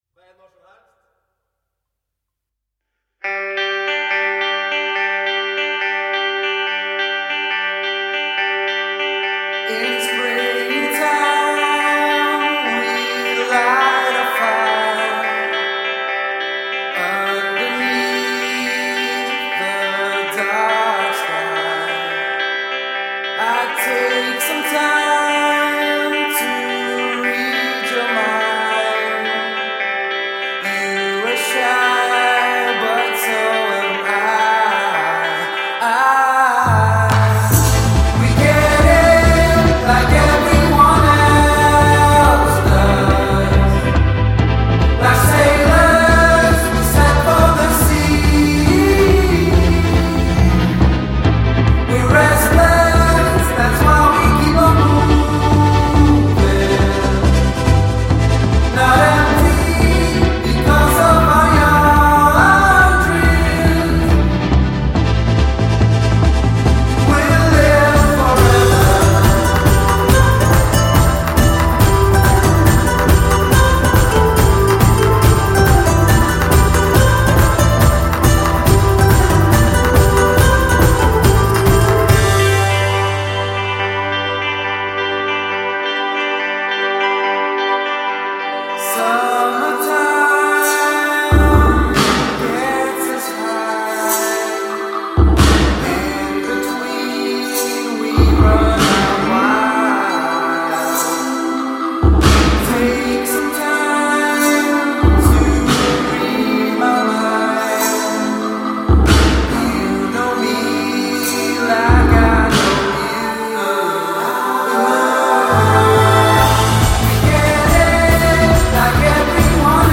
pop tropicale